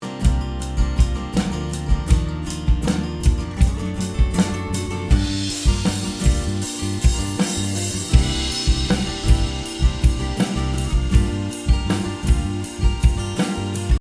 It might have sounded pretty good during recording but when it comes time to mix it’s kind of lacking compared to the other instruments.
The tutorial will teach you how to take a bass track from this: